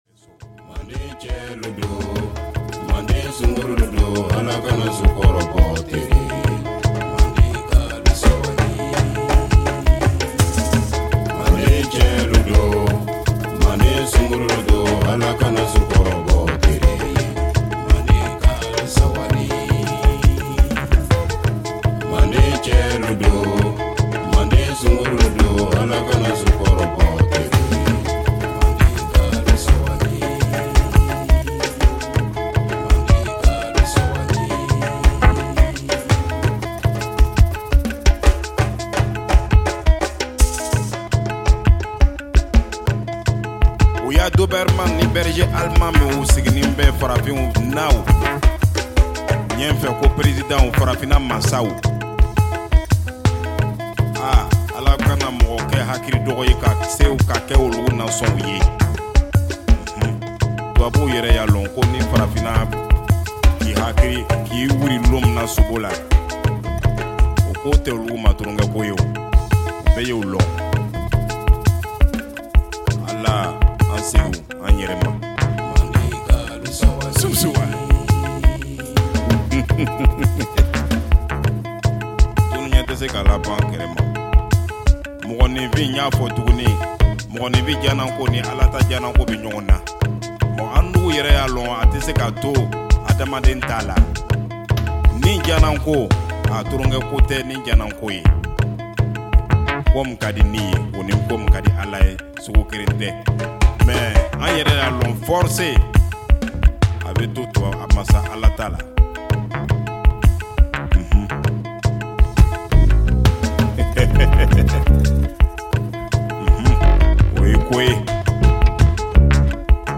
original African instruments with techno and deep house
House Outernational